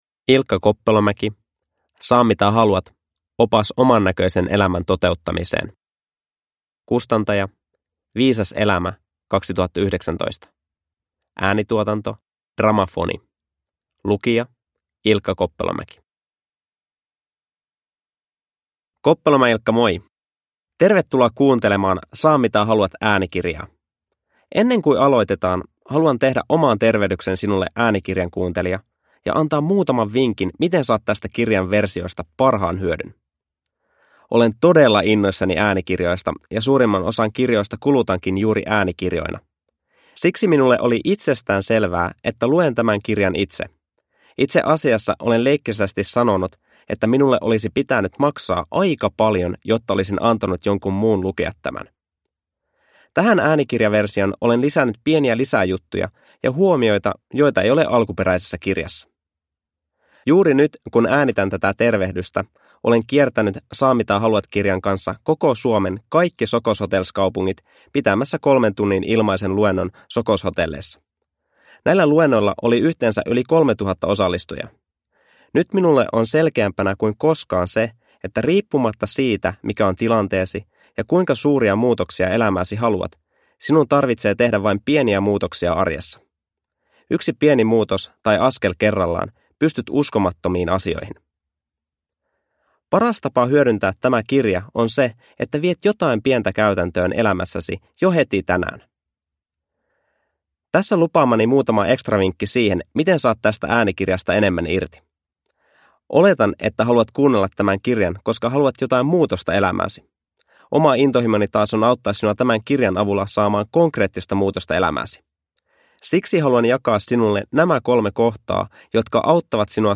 Saa mitä haluat – Ljudbok – Laddas ner